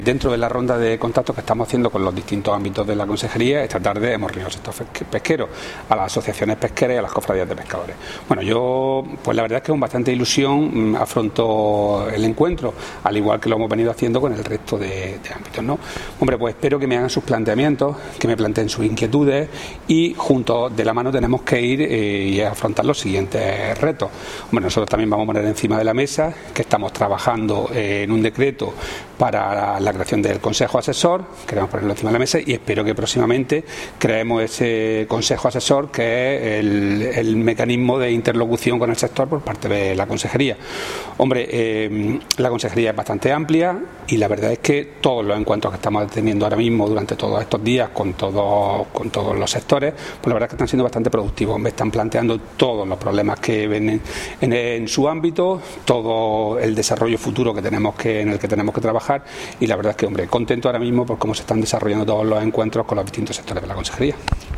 Declaraciones consejero sobre reunión